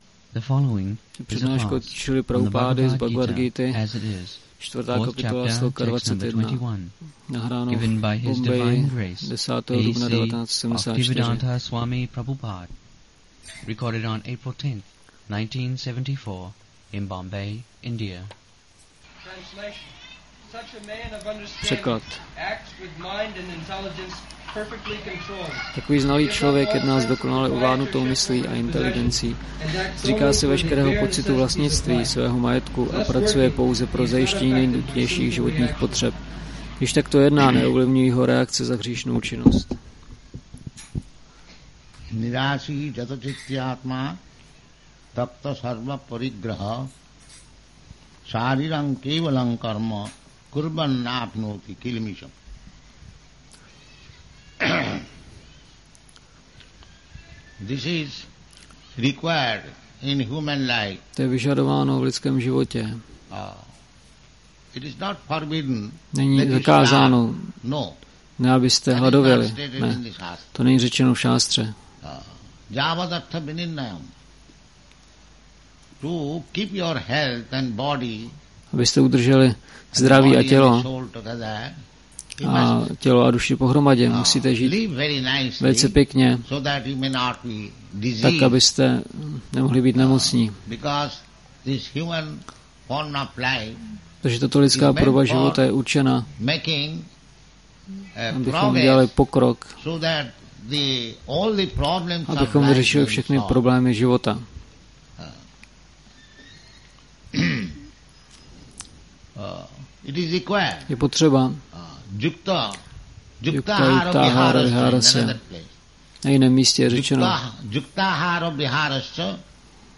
1974-04-10-ACPP Šríla Prabhupáda – Přednáška BG-4.21 Bombay